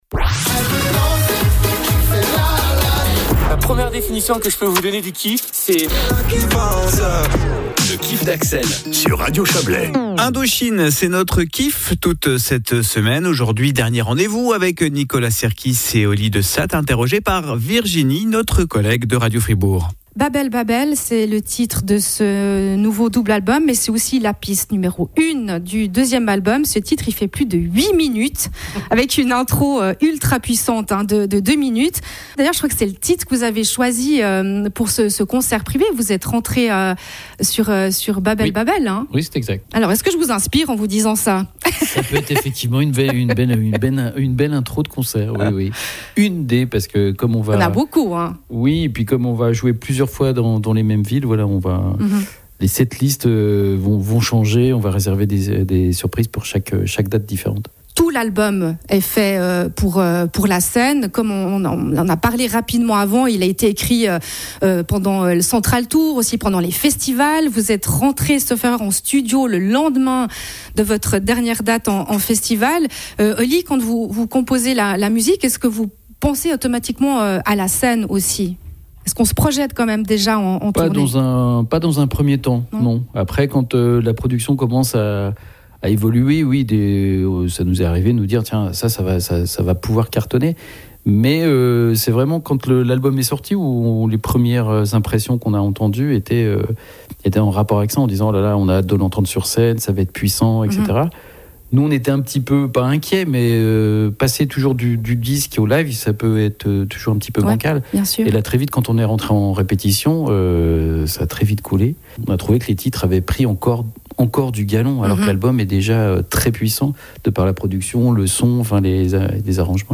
Une interview